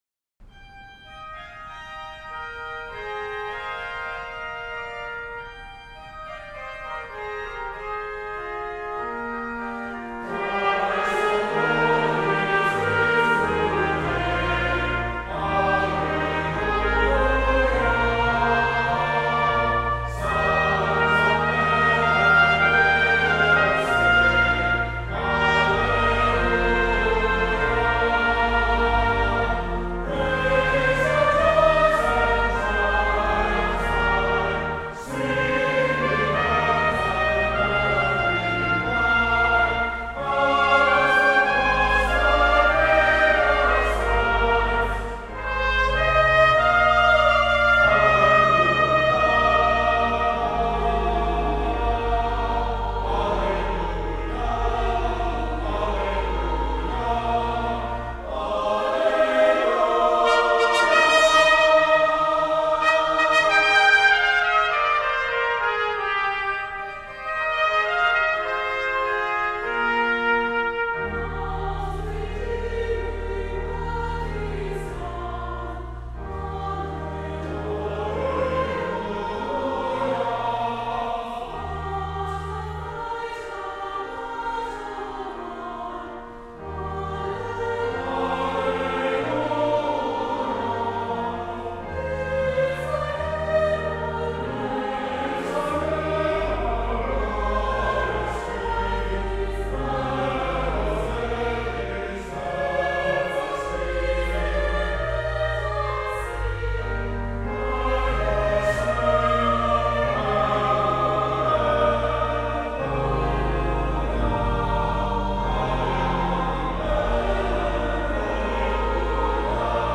Easter Sunday 2021 | Cann Memorial Presby
Easter Music
Choral Anthem: Christ the Lord is Risen Today by Mark Schweitzer